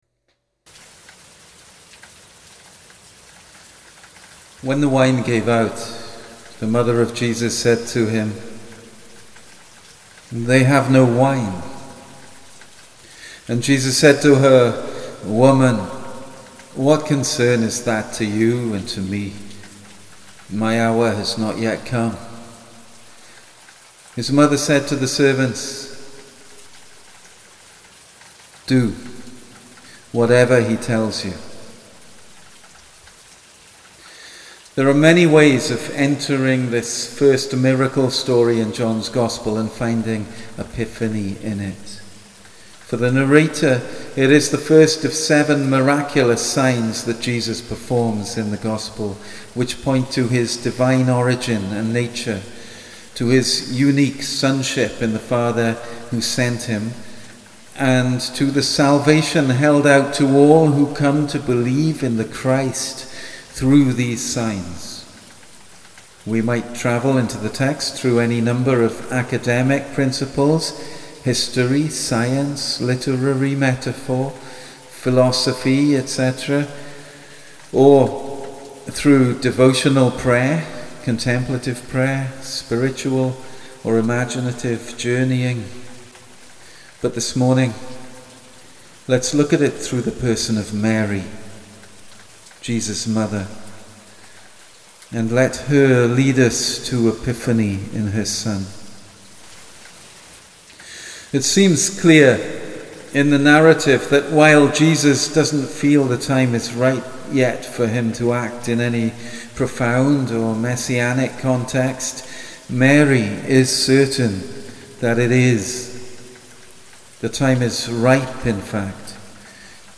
a sermon for epiphany in one of the churches on the marsh
live recording